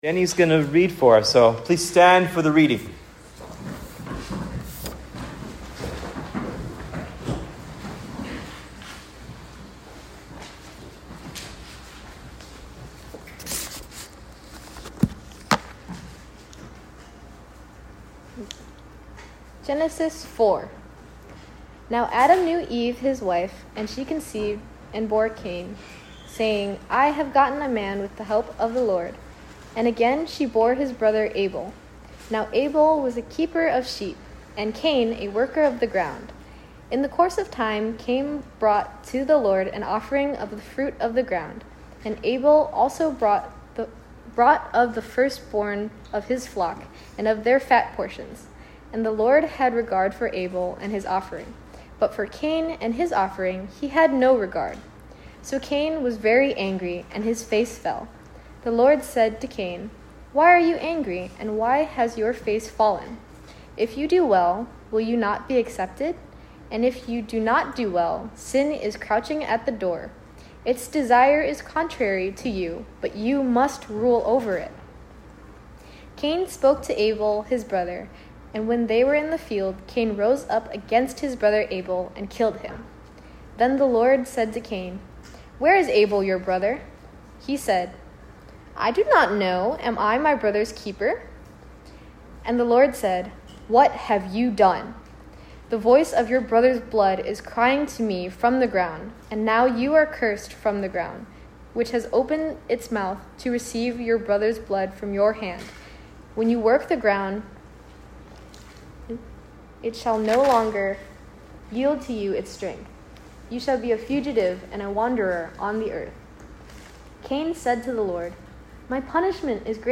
Genesis 4 Sermon “Cain and Abel”